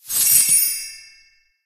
bp_coinpack_purchase_01.ogg